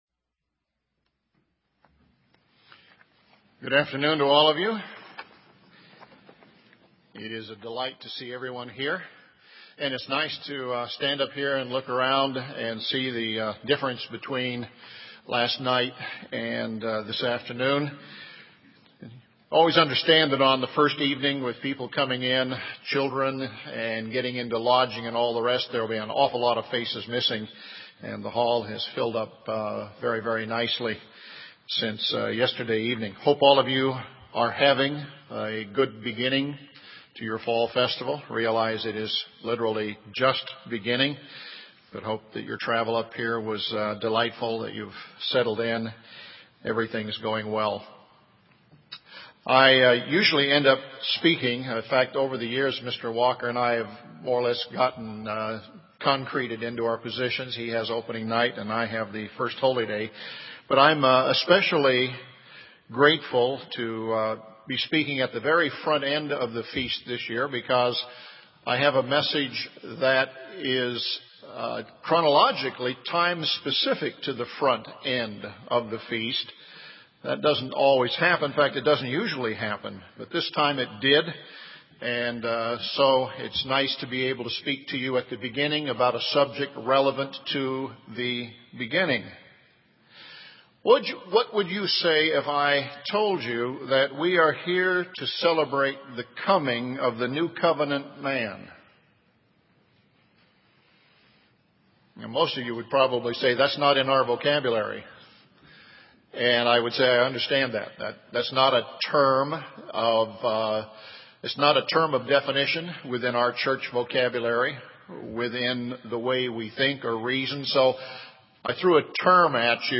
This sermon was given at the Bend, Oregon 2011 Feast site.
UCG Sermon Studying the bible?